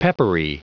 Prononciation du mot peppery en anglais (fichier audio)
Prononciation du mot : peppery